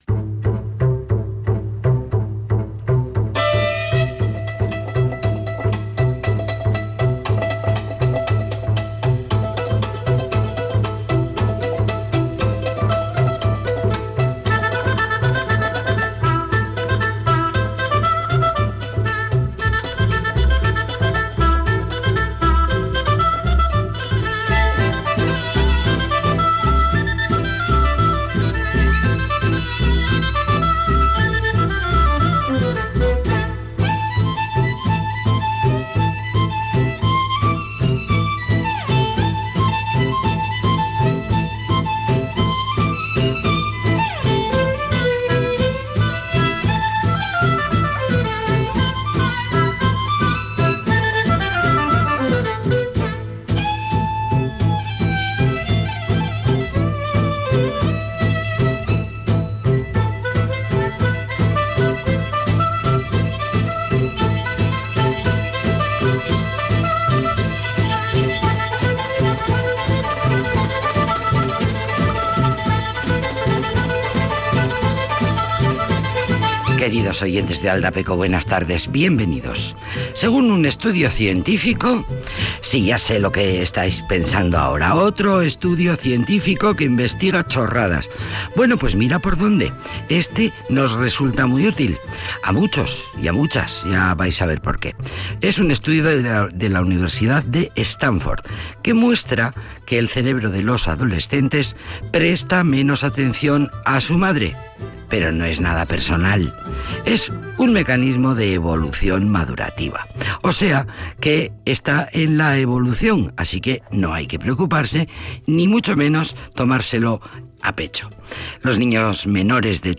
Múisca y entrevistas para la sobremesa